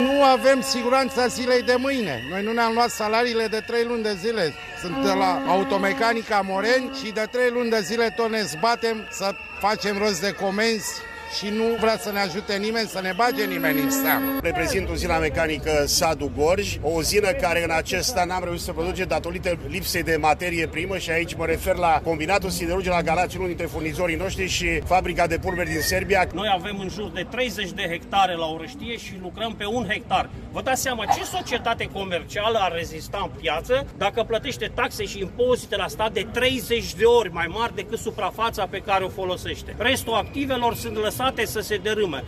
Protestatarii spun că autorităţile sunt complet dezinteresate de problemele cu care se confruntă companiile de stat din acest domeniu: